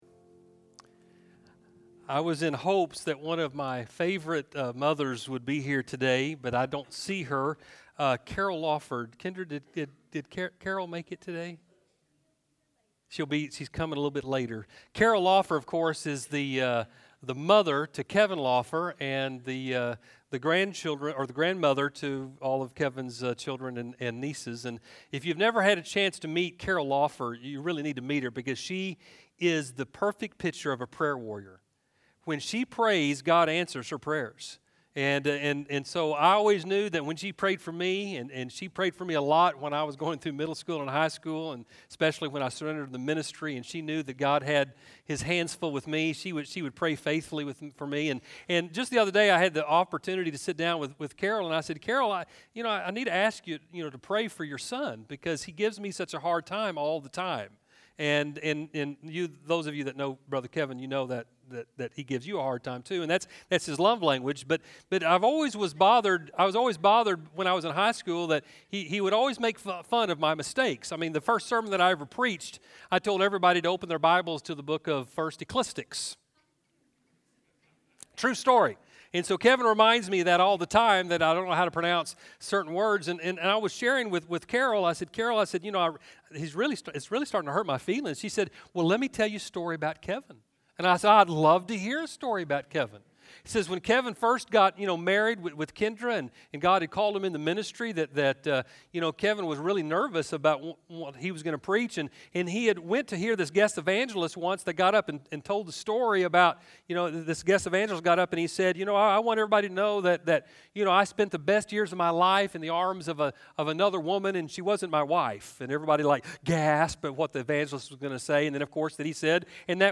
A-faith-that-lives-on-sermon.mp3